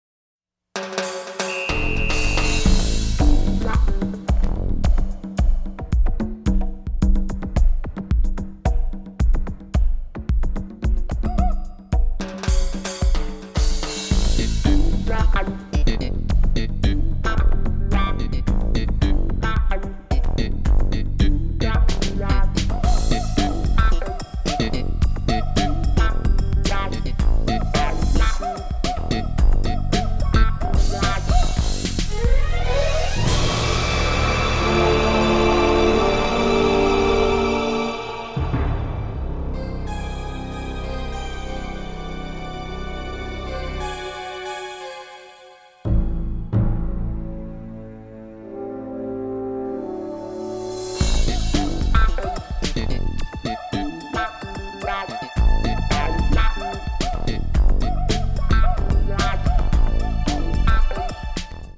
primarily synths
strings, winds, and heavy percussion